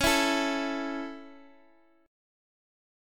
A5/C# chord
A-5th-Csharp-x,x,x,6,5,5.m4a